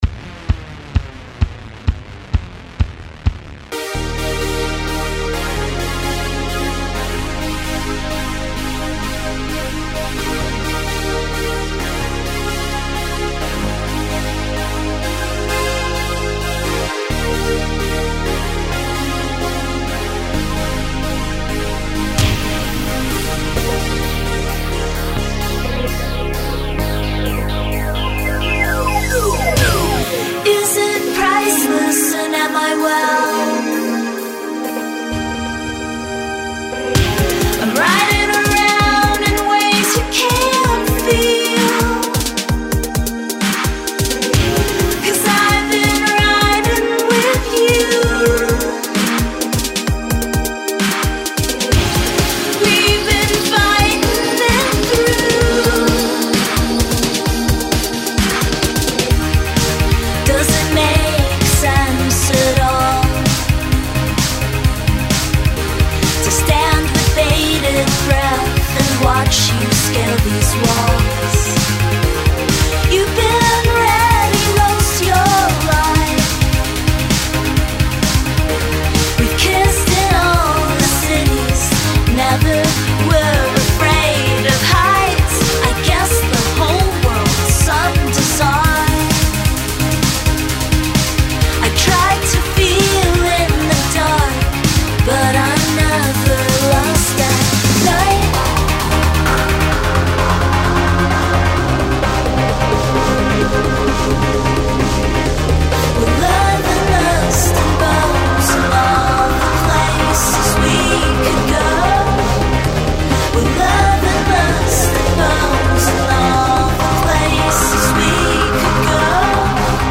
The melodic synth layers
Its a gorgeous synth track perfect for the warm summer […]